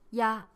ya5.mp3